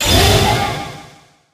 kaiju_jump_01.ogg